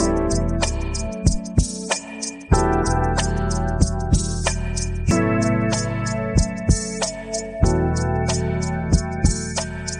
Here is a brief selection based on the text prompt “exciting interlude”. It would not describe it as exciting.